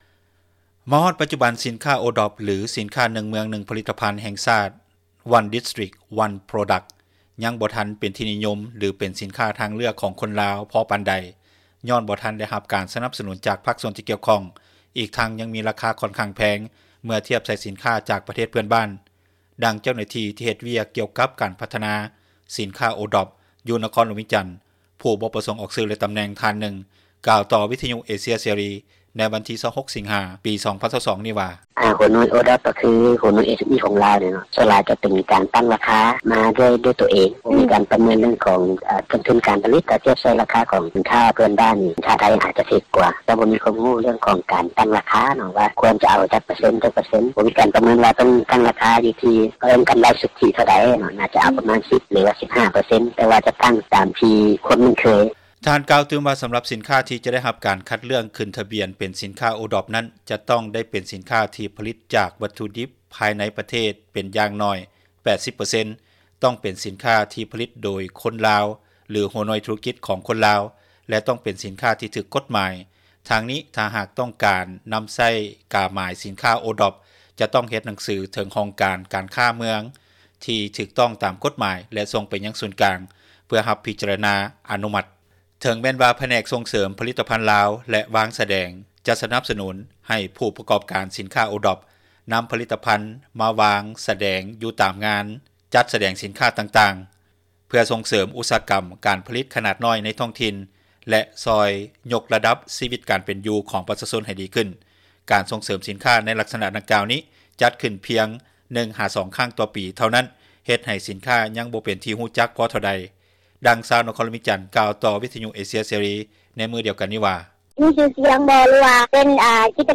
ດັ່ງຊາວນະຄອນຫຼວງວຽງ ກ່າວຕໍ່ວິທຍຸເອເຊັຽເສຣີ ໃນມື້ດຽວກັນນີ້ວ່າ: